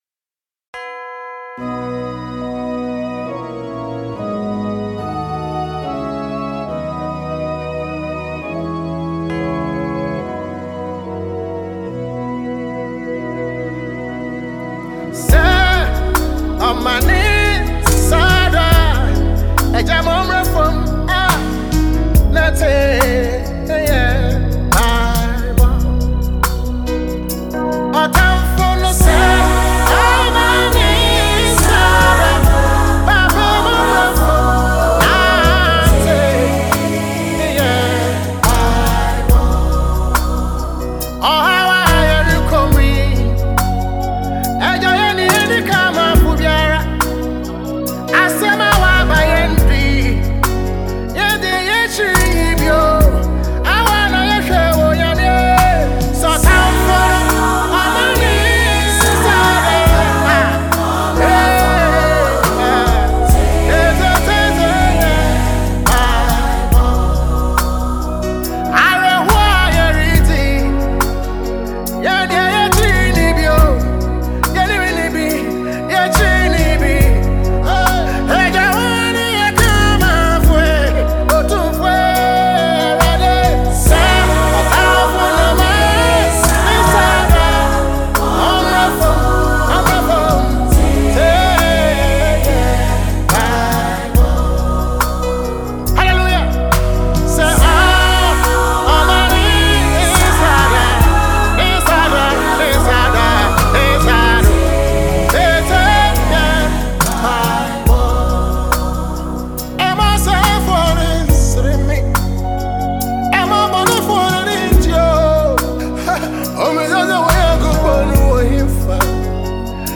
a Ghanaian artist and music producer
worship tune